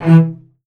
Index of /90_sSampleCDs/East West - The Ultimate String Collection/Partition G/VCS MARCATO